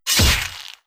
Melee Weapon Attack 8.wav